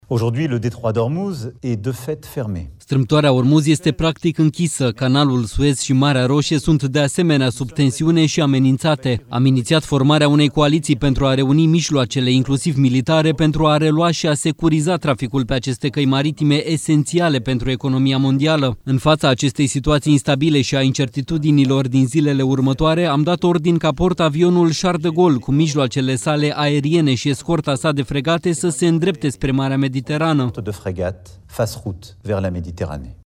Acesta a declarat, într-un discurs către națiune, că Iran poartă principala responsabilitate pentru războiul din Orientul Mijlociu.
04mar-13-Macron-trimit-portavionul-Charles-de-Gaulle-tradus.mp3